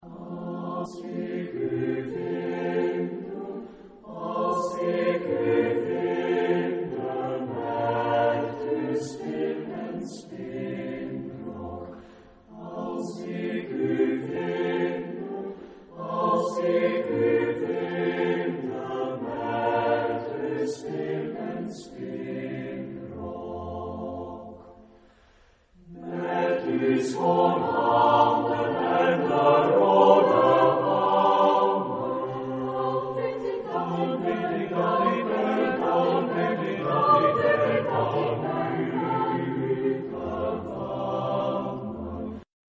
Genre-Style-Forme : Renaissance ; Profane
Type de choeur : SATB  (4 voix mixtes )
Tonalité : fa majeur